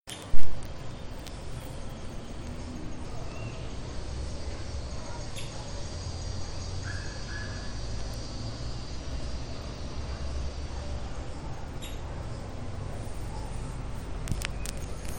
Pica-pau-chorão (Veniliornis mixtus)
Vocalización de una hembra (Del Viso)
Nome em Inglês: Checkered Woodpecker
Localidade ou área protegida: Gran Buenos Aires Norte
Condição: Selvagem